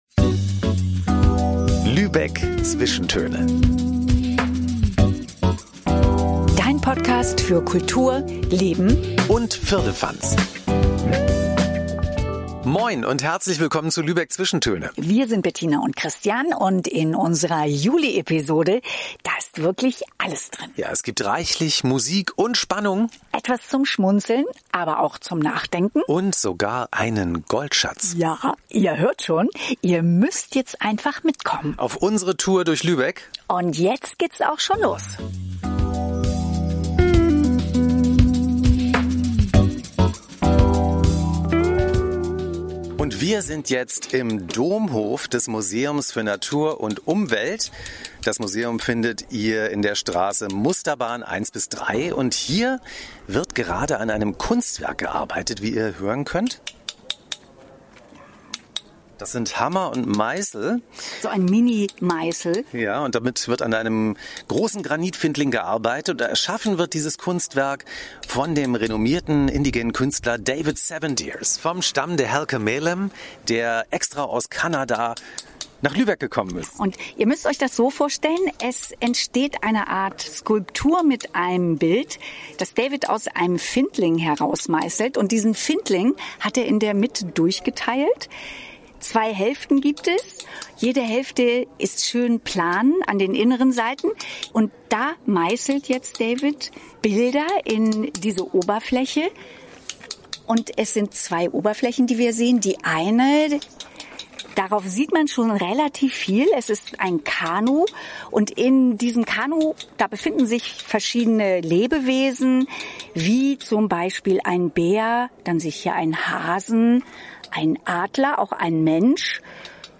Es gibt reichlich Musik und Spannung, etwas zum Schmunzeln, aber auch zum Nachdenken und sogar einen Goldschatz.
An warmen Sommertagen kannst du dich in kühlen Kirchen von Orgelklängen inspirieren lassen.